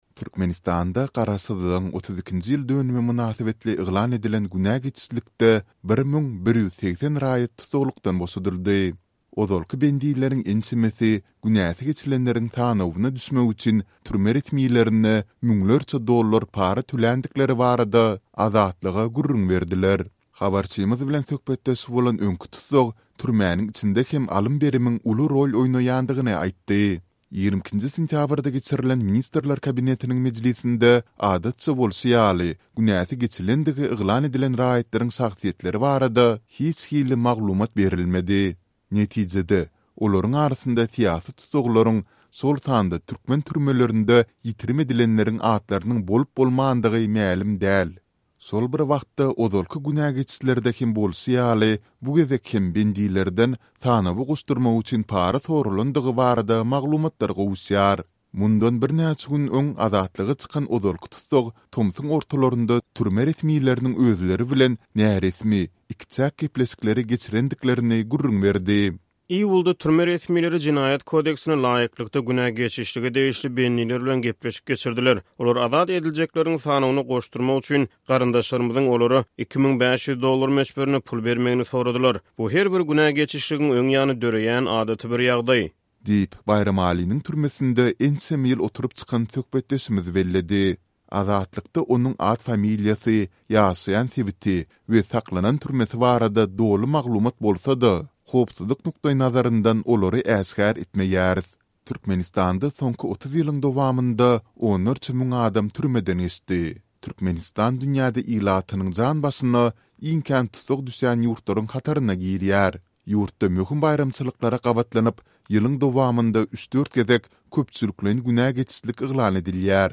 Diňle: Garaşsyzlyk mynasybetli günäsi geçilenleriň sanawyna goşdurmak üçin ‘para soraldy’ – öňki tussag